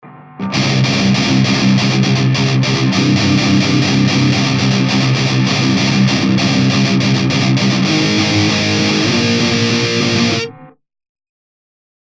Зацените обработку в кубейсе, подскажите, чего не хватает (сурс не мой).
Без обид, но пока что я слышу лязг консервных банок, и любой железный перегруз снятый микрофоном будет звучать в разы приятнее для ушек.